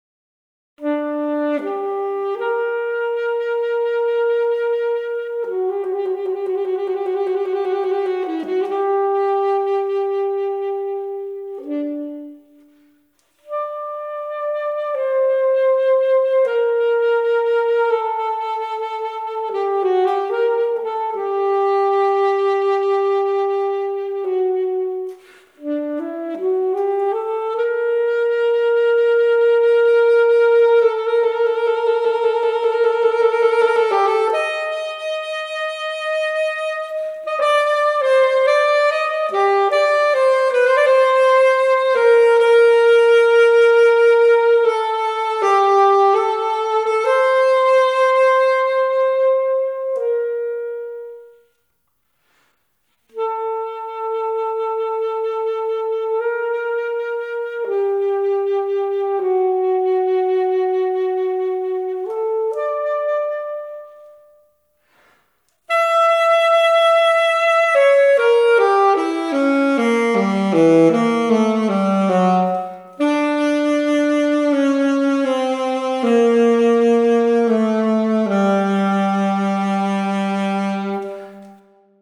(VIBRATO NATUREL ET SAXOPHONE)
une recherche d'expression, de timbre et de justesse, fondée sur le jeu du hautbois,
(Largo, enregistré le 08.09.09) *